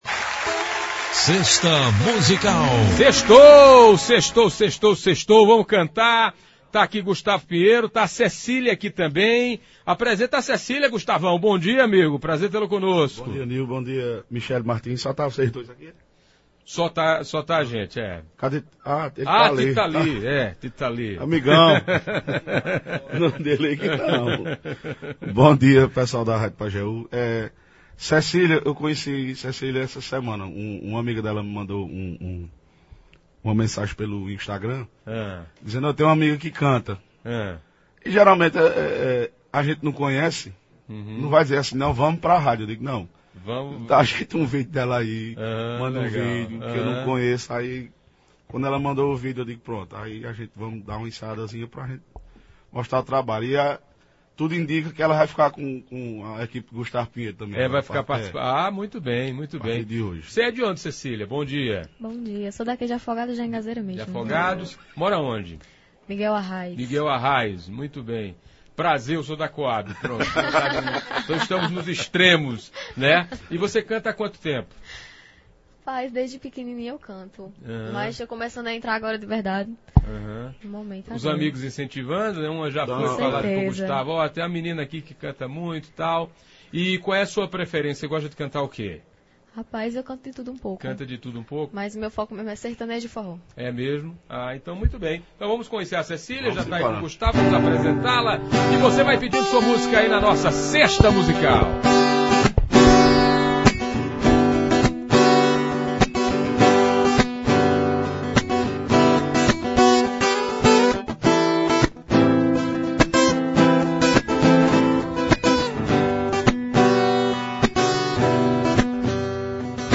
Diz cantar de tudo, mas tem como ritmos preferidos, o sertanejo e o forró.